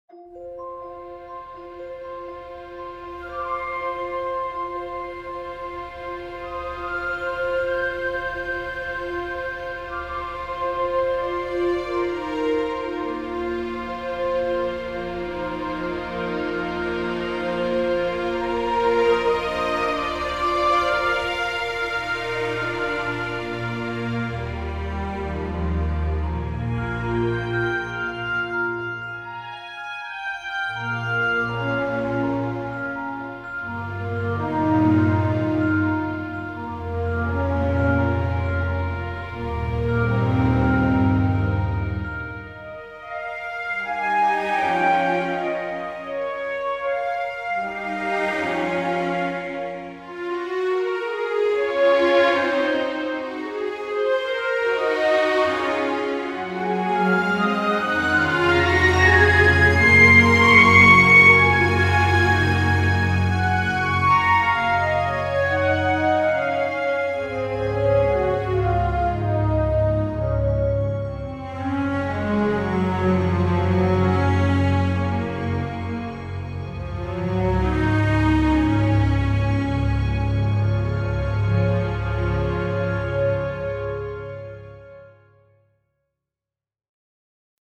打击乐器
弦乐
STRING ENSEMBLE 拥有紧凑、饱满而自然的音色，并配备先进的演奏控制功能，可呈现丰富的细节和清晰的音质。
黄铜
木管乐器